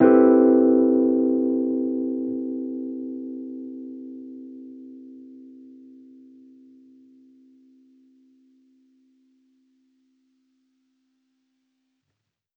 Index of /musicradar/jazz-keys-samples/Chord Hits/Electric Piano 1
JK_ElPiano1_Chord-Cmaj13.wav